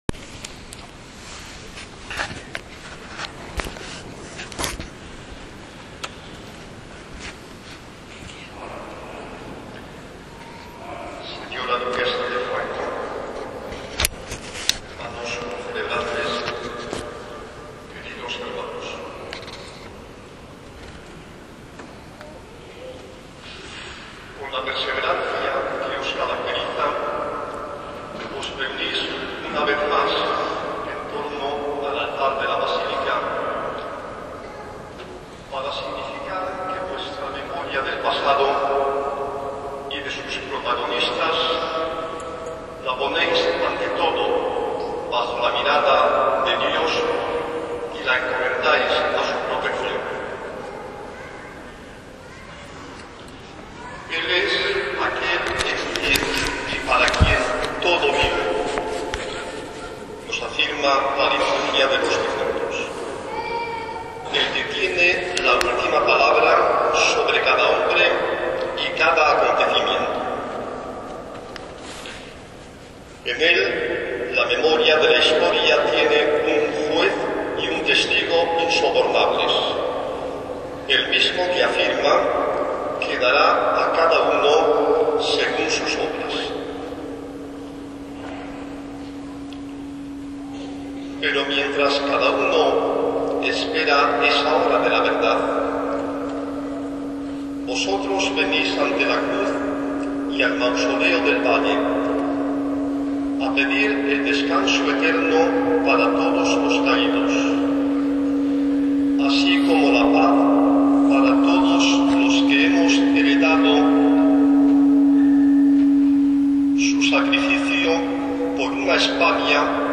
Reportaje fotogr�fico de la Misa en el Valle de los Ca�dos.
Descarga del audio de la Homil�a, 8,53 Mb.